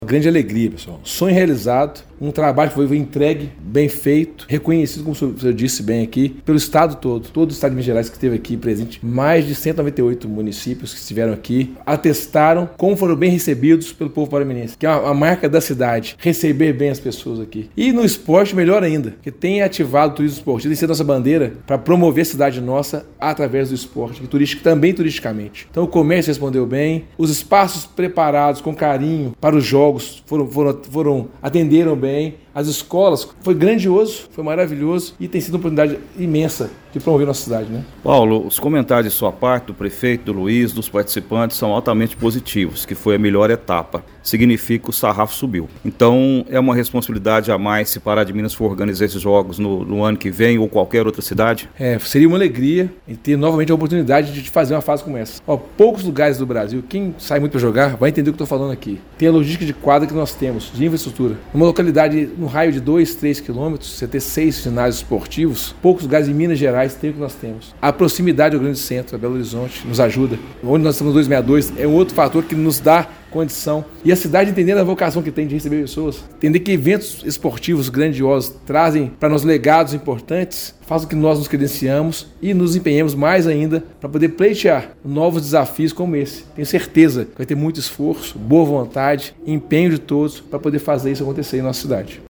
Paulo Francisdale destacou a união entre esporte e educação como chave para o sucesso.